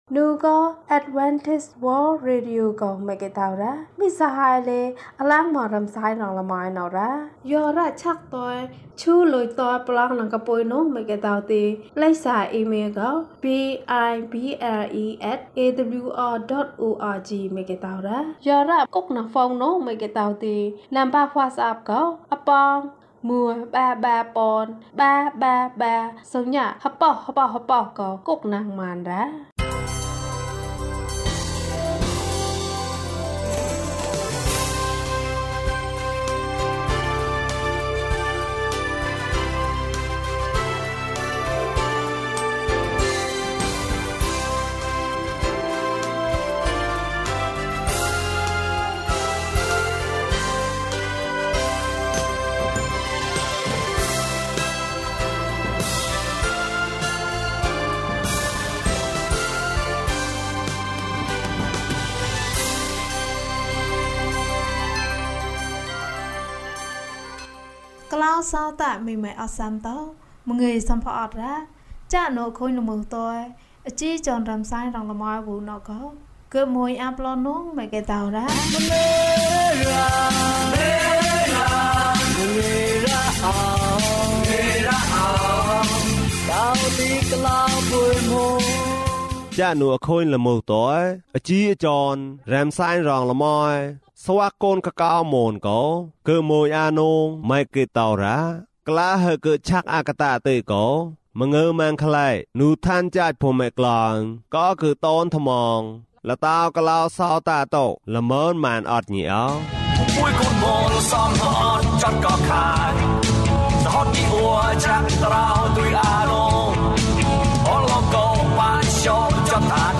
ခရစ်တော်ထံသို့ ခြေလှမ်း ၁၇။ ကျန်းမာခြင်းအကြောင်းအရာ။ ဓမ္မသီချင်း။ တရားဒေသနာ။